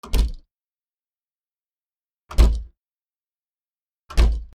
ドア トイレ
『ガチャ』